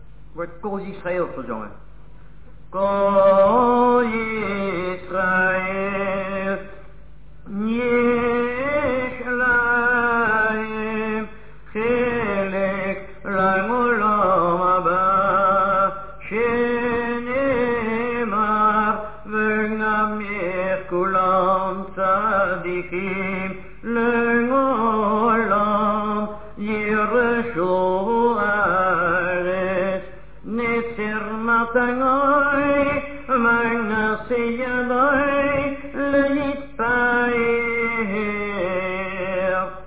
This structure is used during a private Limud (Lezing) and on 15 Shevat.